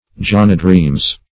\John"a*dreams`\